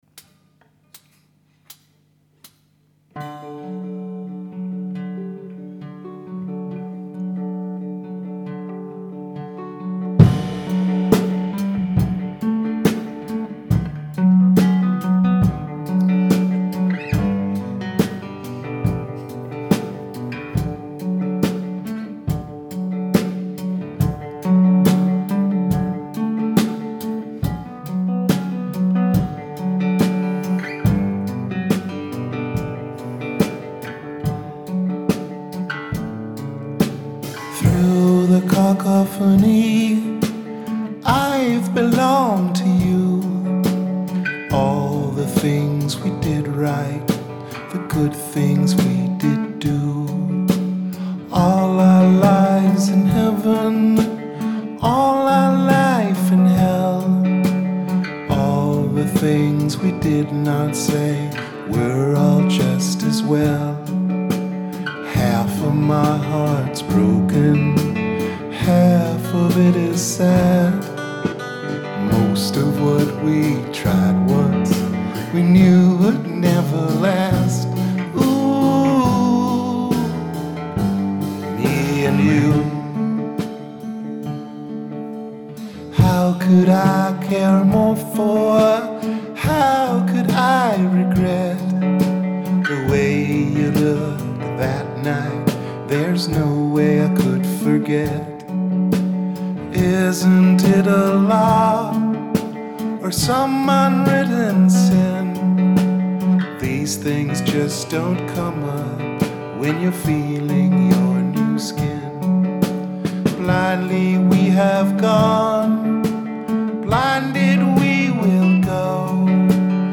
Me and You.. just a practice recording 20.4.
Me And You 20.4.2012 Me_and_you_20_4_2012_Mix1_MaErMi.mp3 The Available Rehearsals 20.4. Author: The Available Category: Rehearsal recordings Date: April 23, 2012 8.6 MiB 7 Downloads Details…